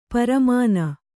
♪ paramāna